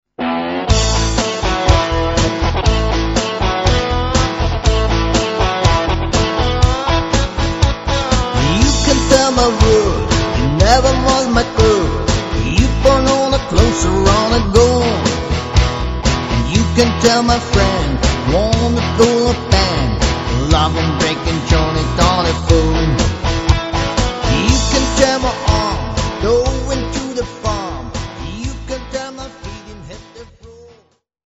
Party-Music-Band